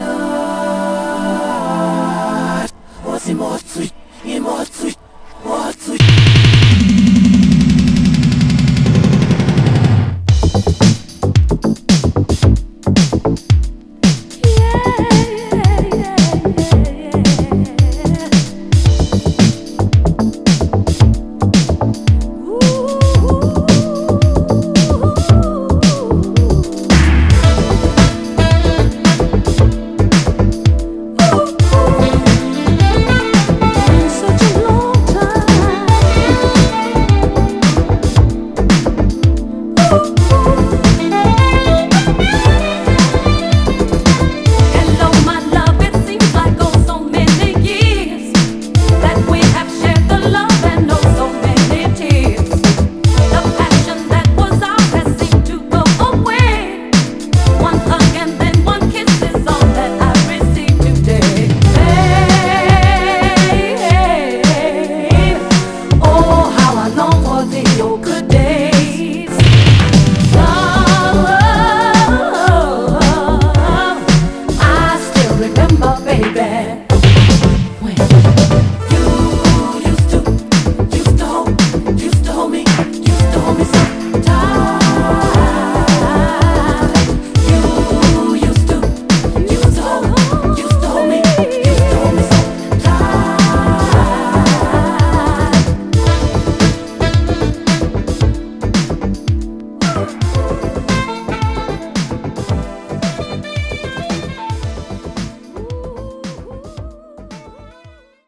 コンクリートなブギー・ファンクがフロアに突き刺さる、人気のモダン・ブギー/ガラージ・クラシックです！
※試聴音源は実際にお送りする商品から録音したものです※